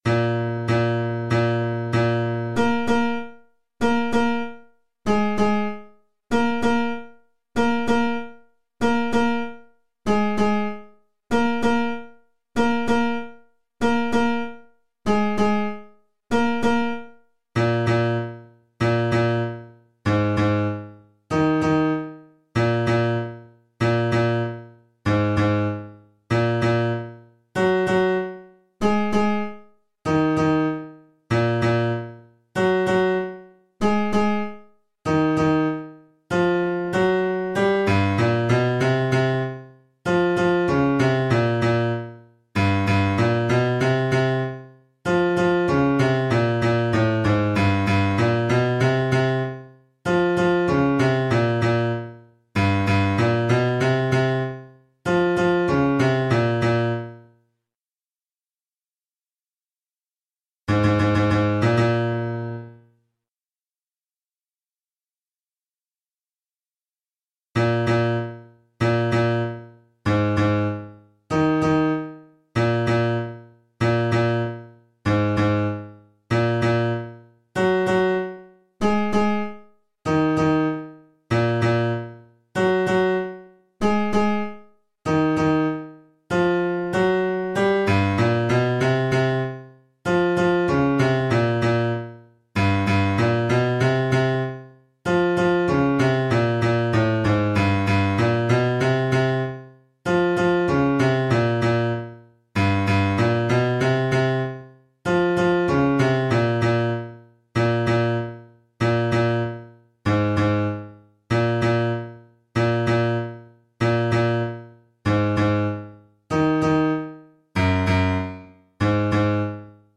Jetzt ist Sommer – Bass_96_bpm
Jetzt-ist-Sommer-Bass-96.mp3